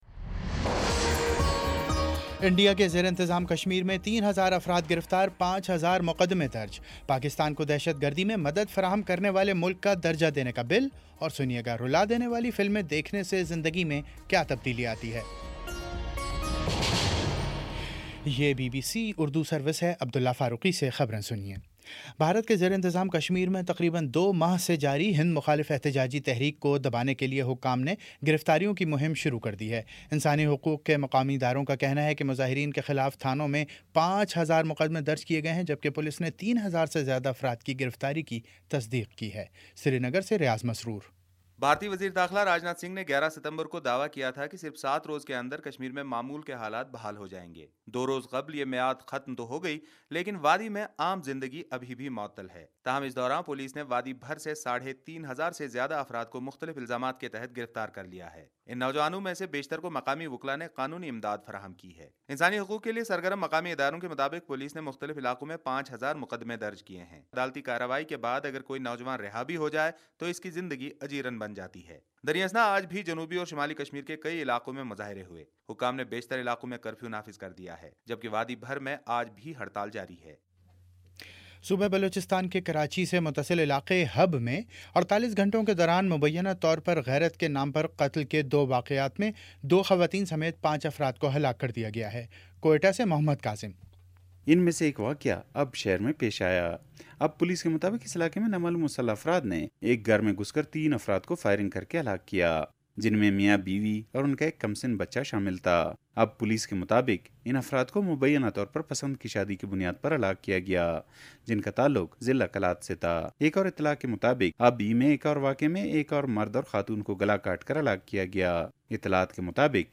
ستمبر 21 : شام پانچ بجے کا نیوز بُلیٹن